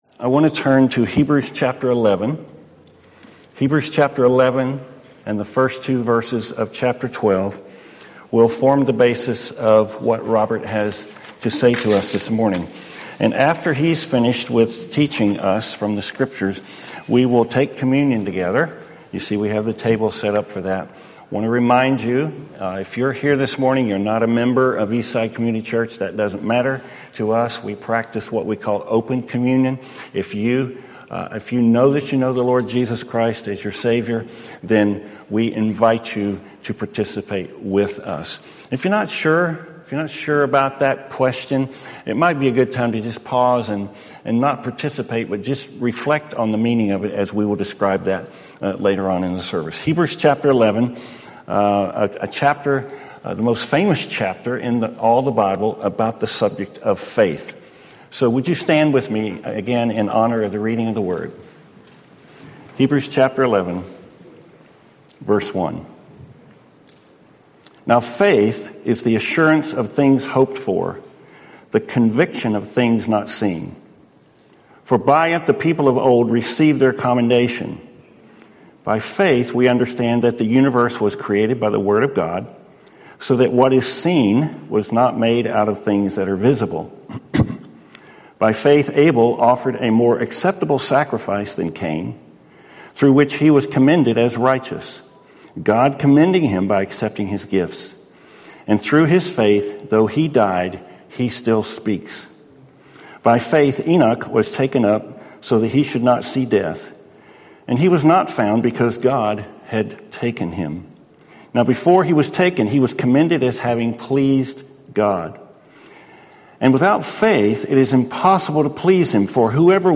Happy Birthday - An Advent Sermon